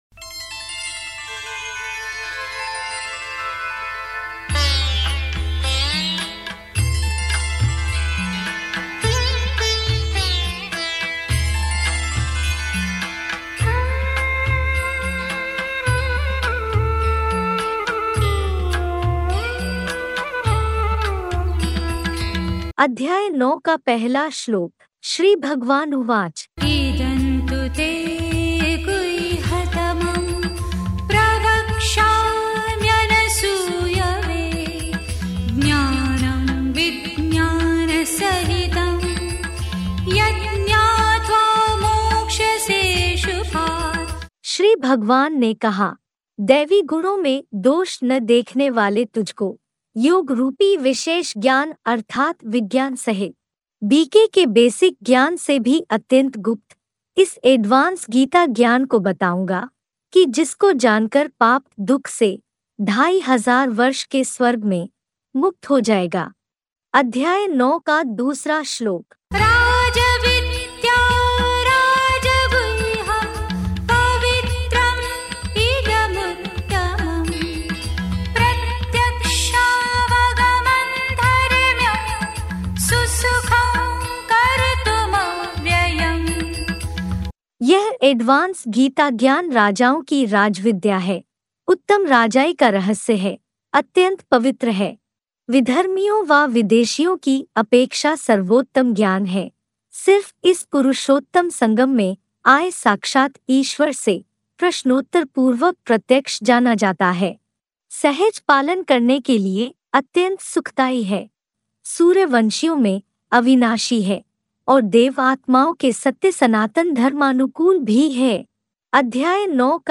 अध्याय -9श्लोक उच्चारण